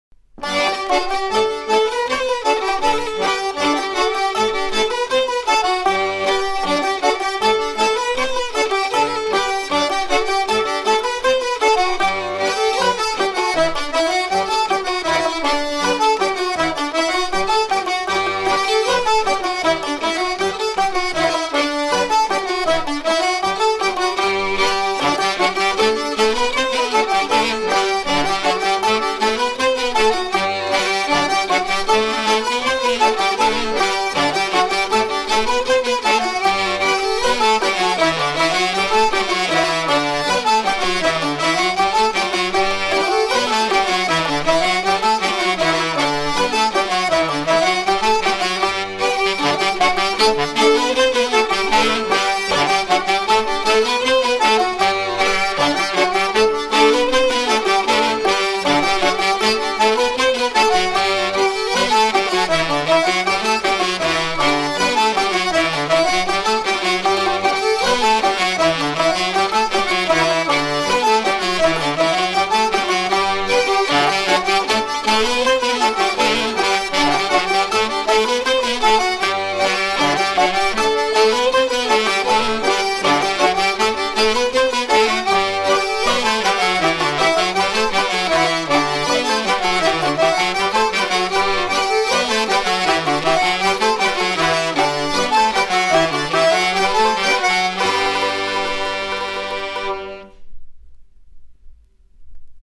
danse : scottich sept pas